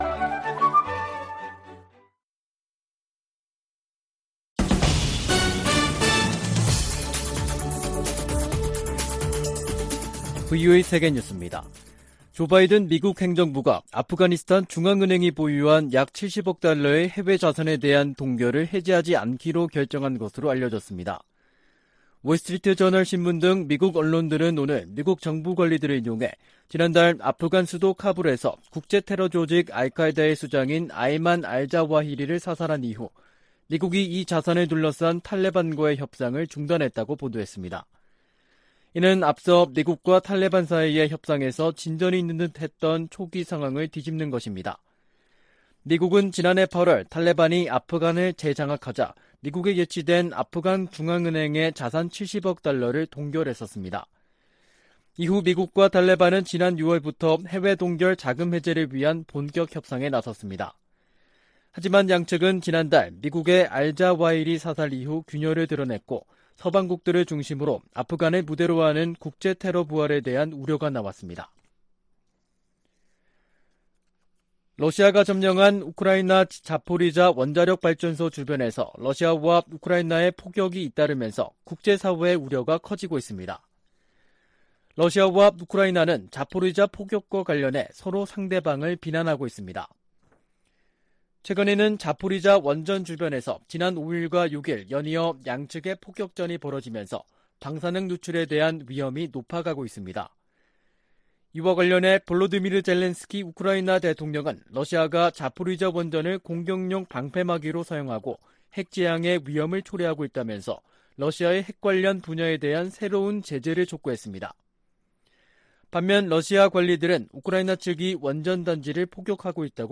VOA 한국어 간판 뉴스 프로그램 '뉴스 투데이', 2022년 8월 16일 2부 방송입니다. 미국과 한국, 일본, 호주, 캐나다 해군이 하와이 해역에서 ‘퍼시픽 드래곤’ 훈련을 진행했습니다. 미 국무부는 한국 윤석열 대통령이 언급한 ‘담대한 구상’과 관련해 북한과 외교의 길을 모색하는 한국 정부를 강력히 지지한다고 밝혔습니다. 에드 마키 미 상원의원이 한국에서 윤석열 대통령과 권영세 통일부 장관을 만나 동맹 강화 방안과 북한 문제 등을 논의했습니다.